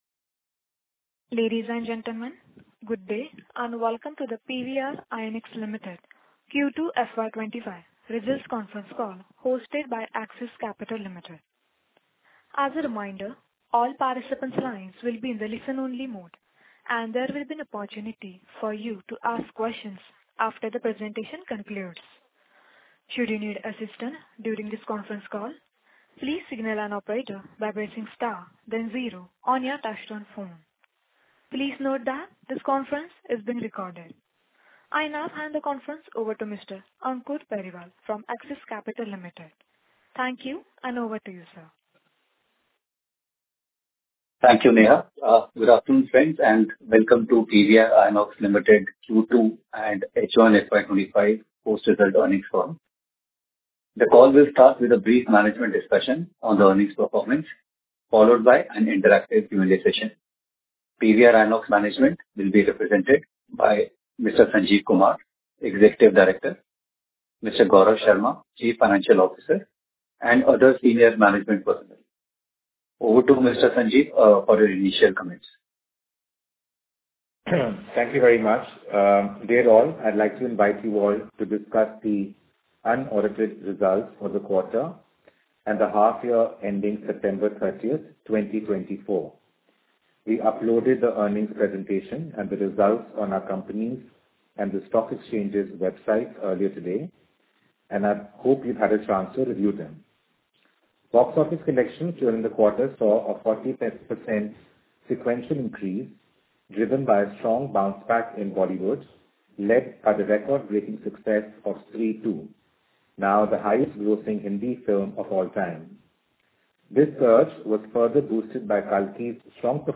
PVR Inox: Q2-25 Earnings Call Highlights